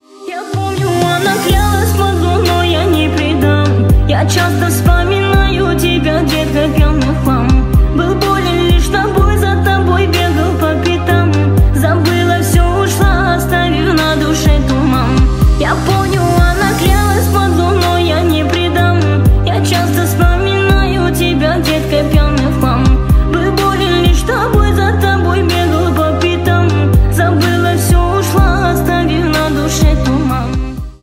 Поп Музыка
грустные # кавер